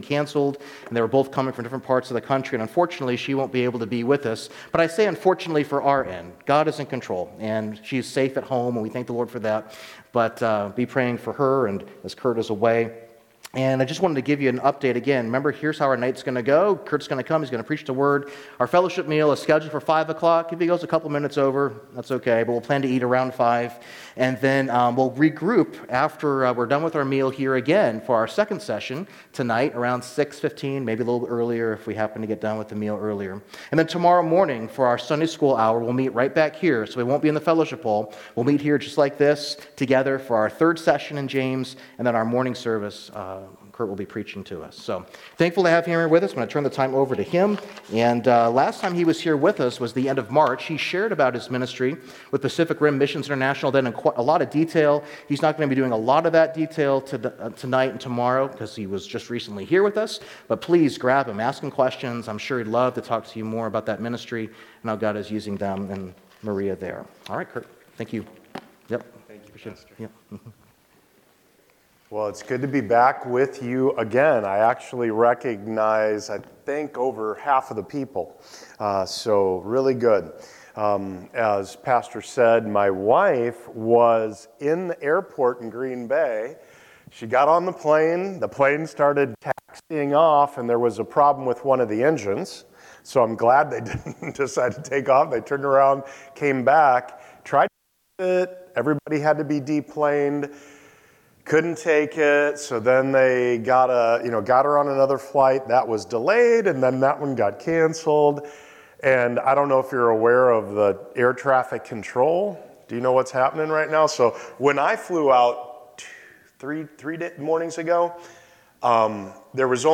Sermons | Open Door Bible Church